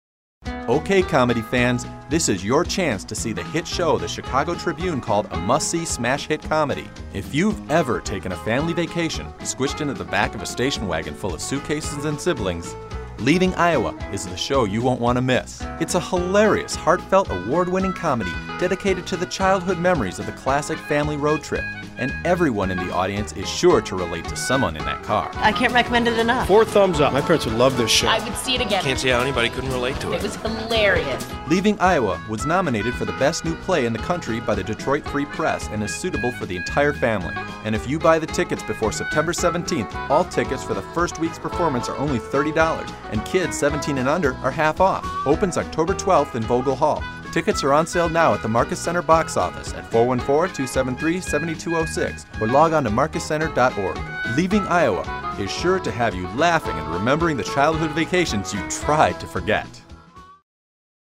Leaving Iowa Radio Commercial